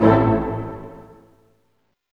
Index of /90_sSampleCDs/Roland L-CD702/VOL-1/HIT_Dynamic Orch/HIT_Orch Hit min
HIT ORCHM0EL.wav